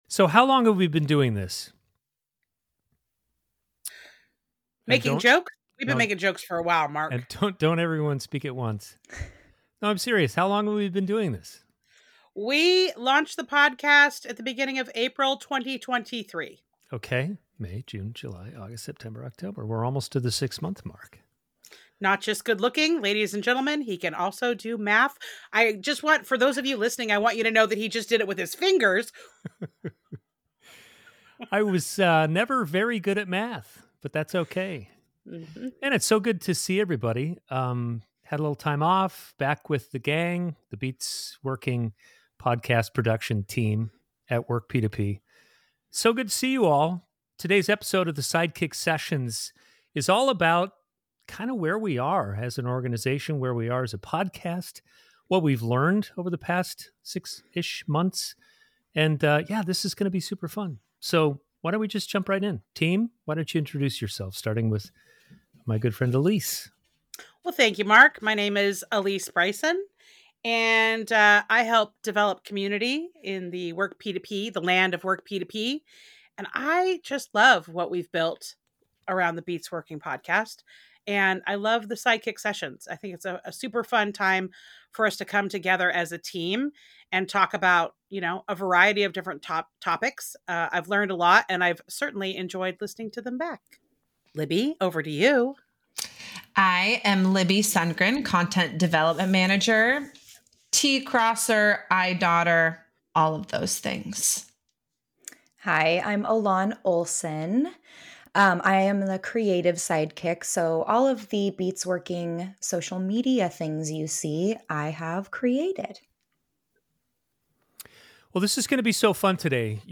Sidekick-Sessions-October-YouTube-Raw-Audio.mp3